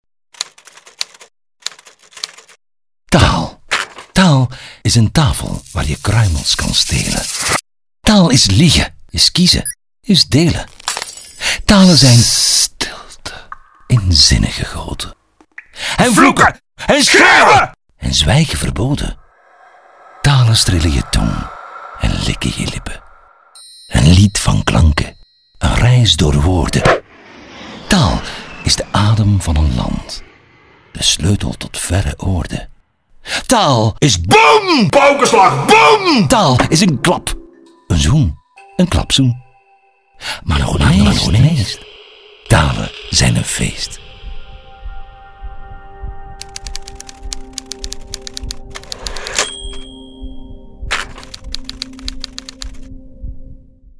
Professional Flemish voice actor.
Sprechprobe: Industrie (Muttersprache):